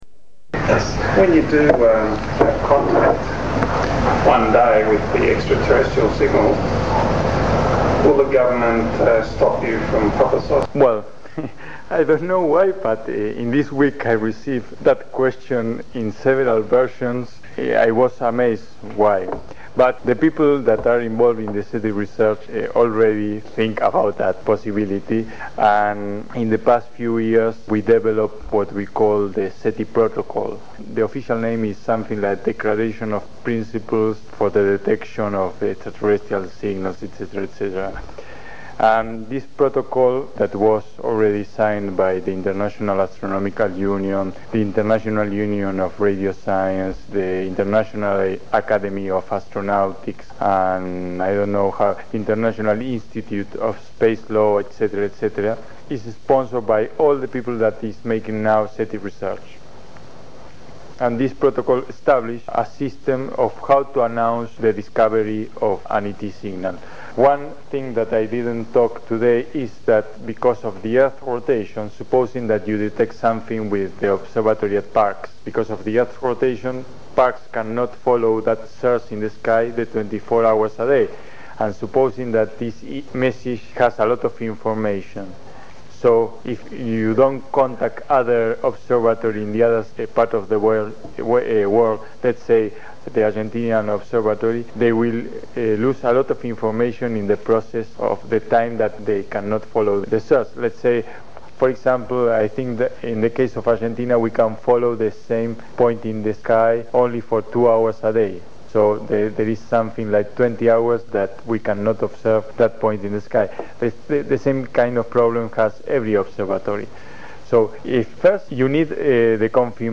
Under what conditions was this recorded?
Argentina Telling the world if evidence for extraterrestrial intelligence is found. Recorded at Space Association of Australia meeting.